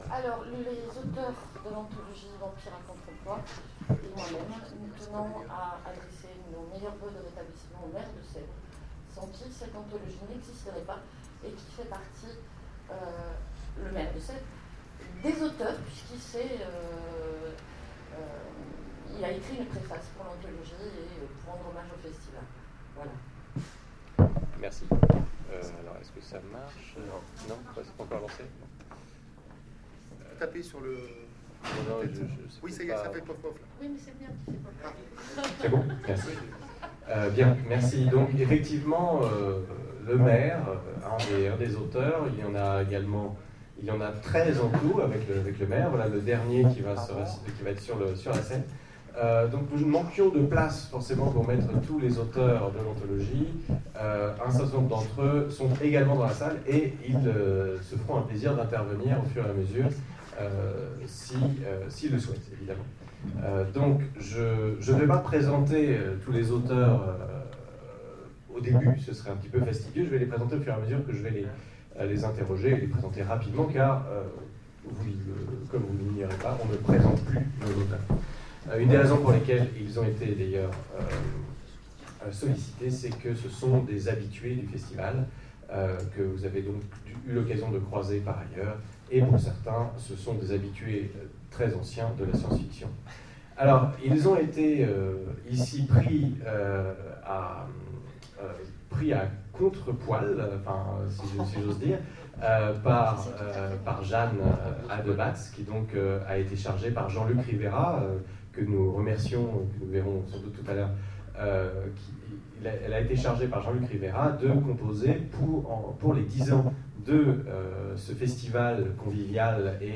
Sèvres 2013 : Conférence Présentation de l'anthologie Vampires à contre-emploi